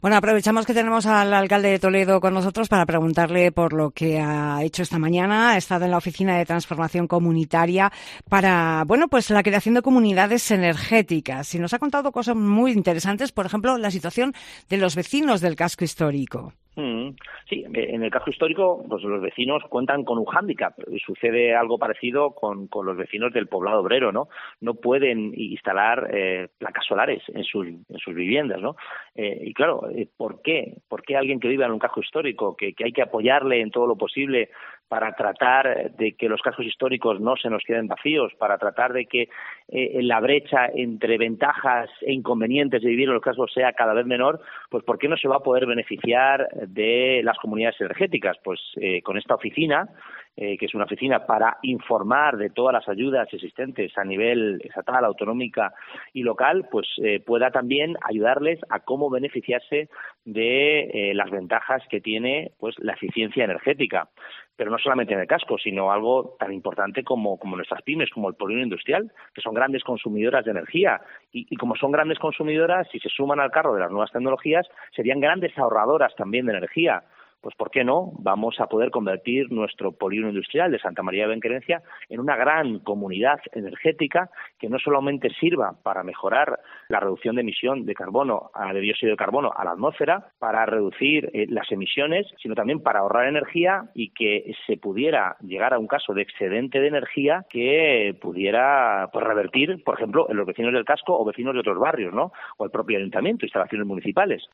En una entrevista al alcalde de Toledo, Carlos Velázquez en COPE Toledo nos ha contado cosas muy interesantes sobre esta oficina ya que, por ejemplo, la situación de los vecinos del casco histórico es diferente.